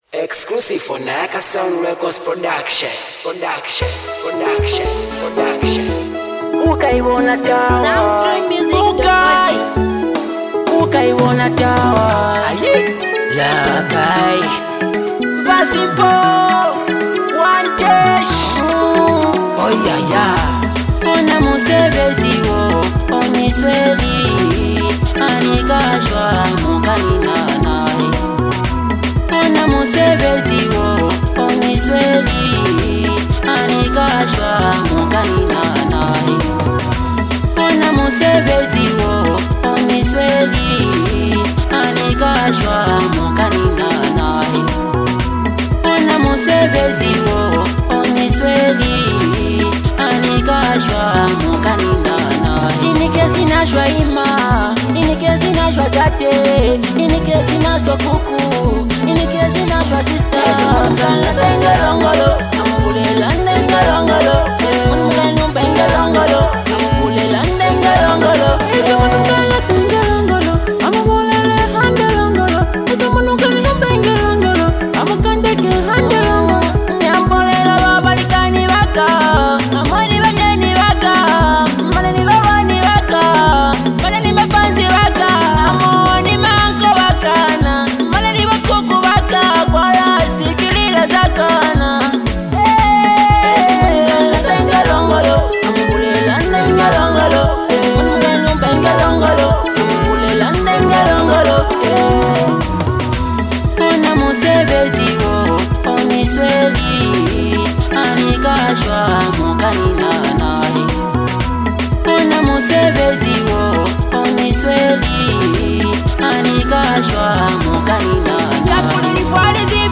With smooth melodies, relatable lyrics, and rich production
Afro-inspired sounds with local influence